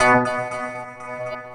Dark Tone.wav